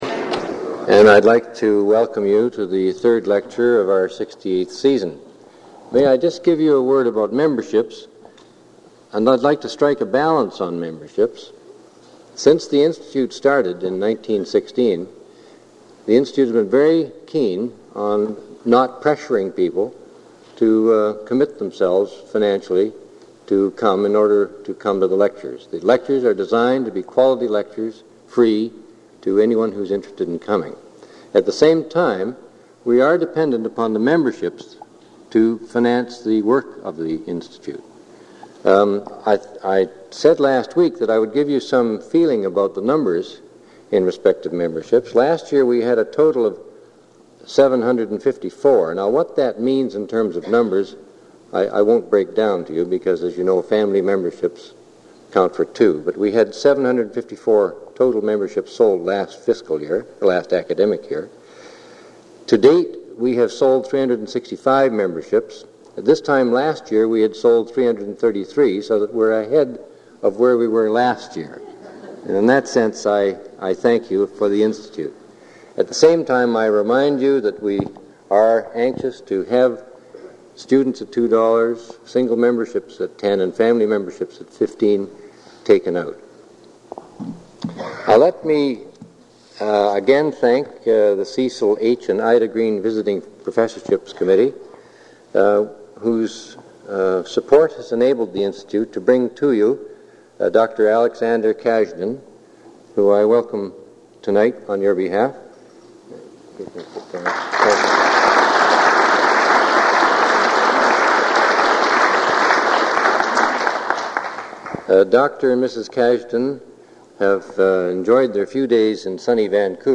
Item consists of a digitized copy of an audio recording of a Cecil and Ida Green lecture delivered at the Vancouver Institute by Alexander Kazhdan on September 29, 1984.